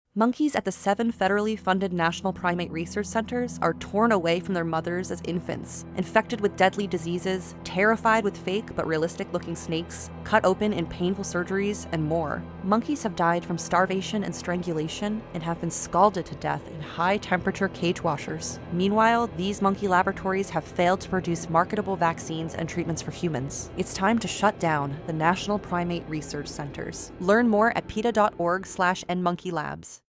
Instructions for Downloading This Radio PSA Audio File
nprc_cruelty_radio_ad_peta.mp3